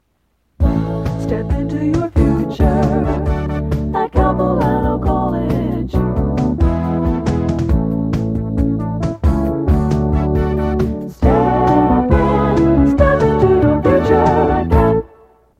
Promotional Audio/Radio Jingle
audio cassette